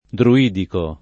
vai all'elenco alfabetico delle voci ingrandisci il carattere 100% rimpicciolisci il carattere stampa invia tramite posta elettronica codividi su Facebook druidico [ dru- & diko ; non dr 2 i- ] agg. (stor.); pl. m. ‑ci